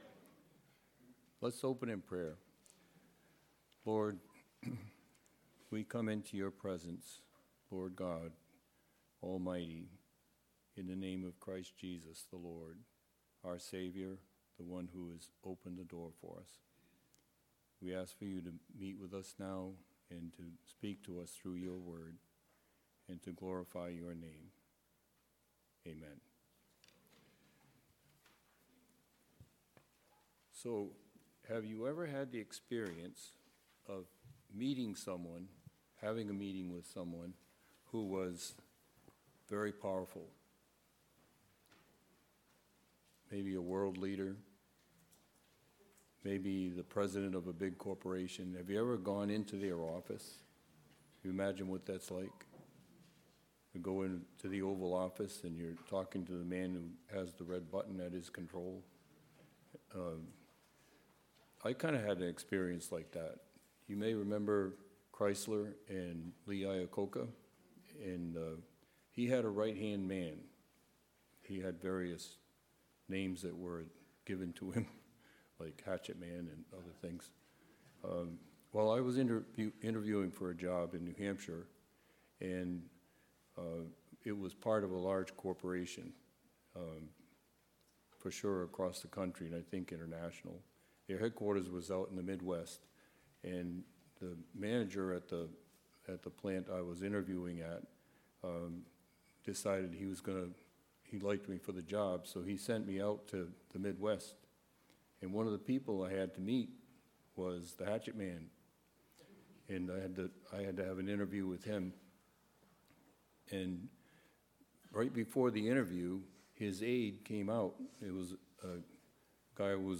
Guest Speaker
Sermons